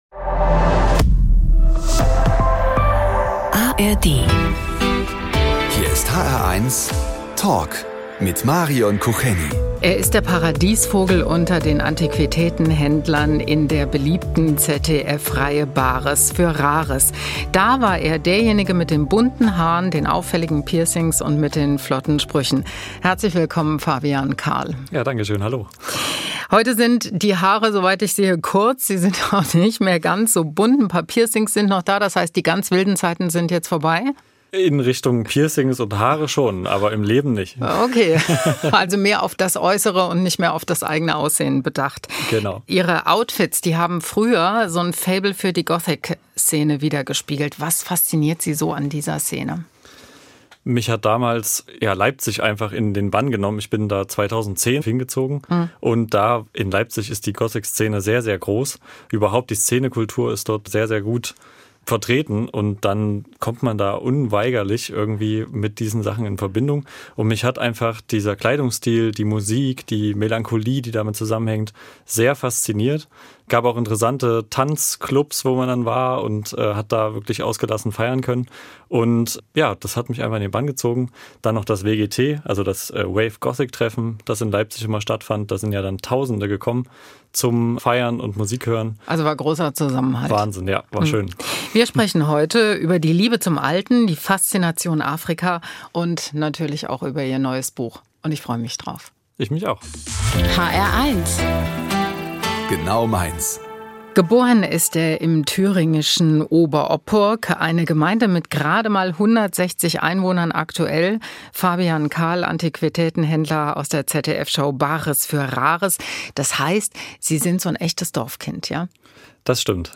Der hr1-Talk mit Fabian Kahl (Wiederholung)